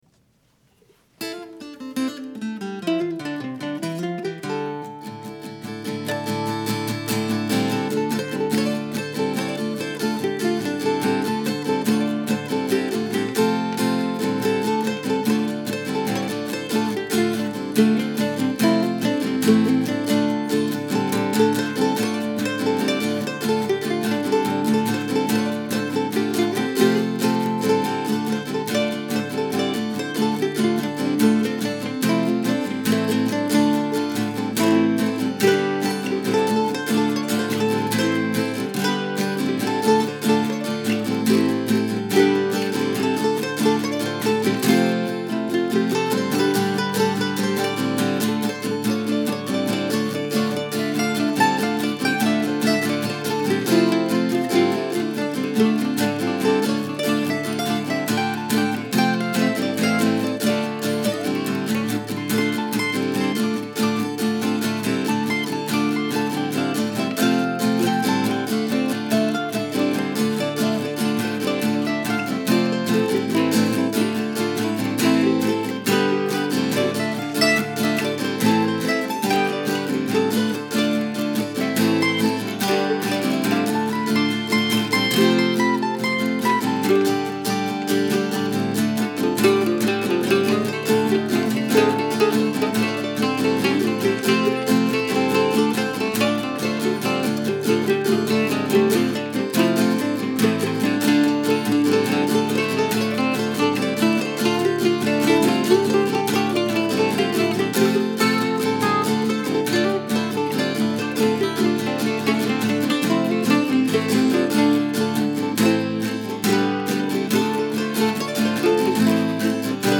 I like this version because I let myself improvise a bit, both in the actual notes played and in some spur of the moment arrangement ideas. It's sloppier than usual but also more fun for me to hear.